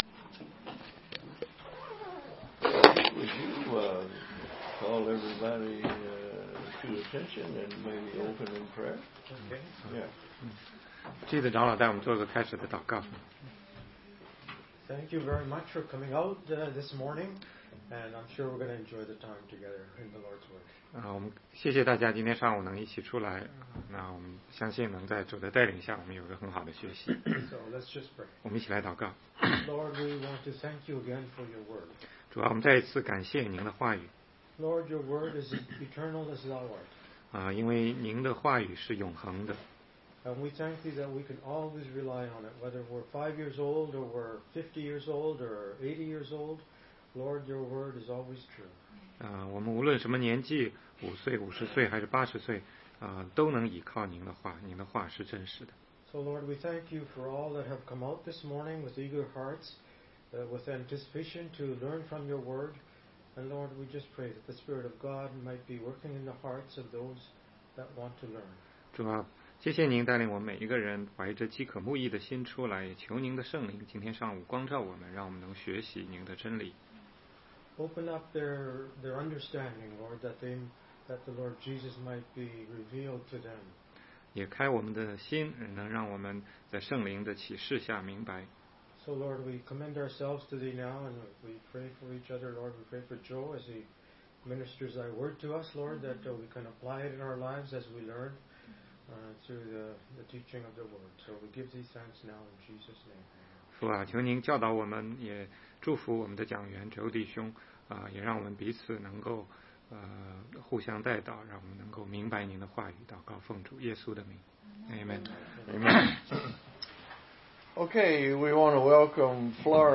16街讲道录音 - 研读圣经的方法示例系列之七